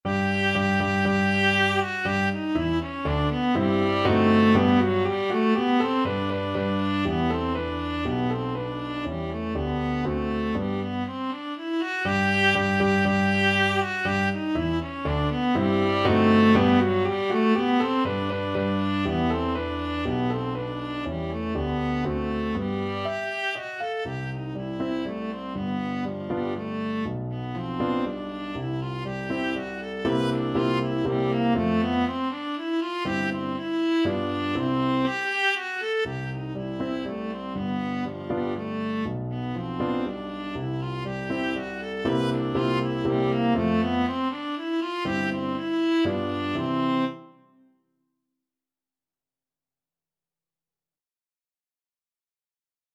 Viola
G major (Sounding Pitch) (View more G major Music for Viola )
Allegro (View more music marked Allegro)
3/4 (View more 3/4 Music)
Classical (View more Classical Viola Music)